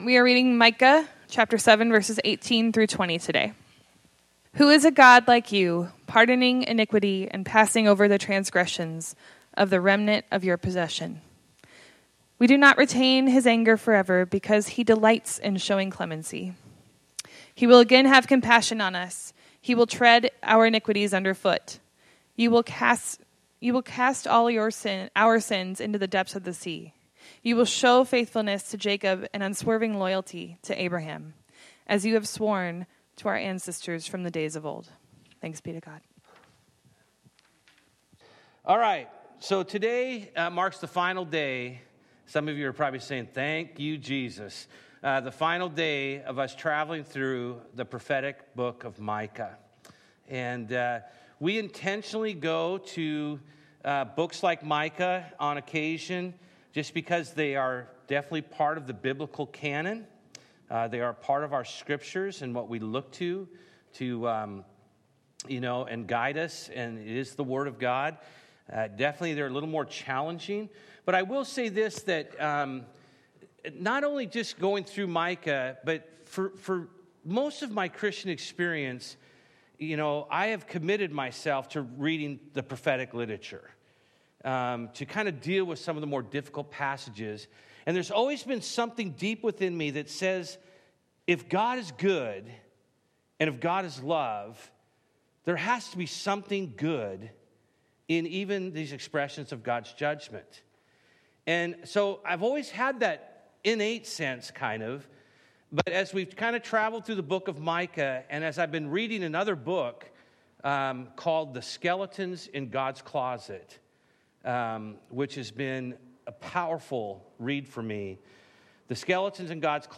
Bible Text: Micah 7:18-20 | Preacher: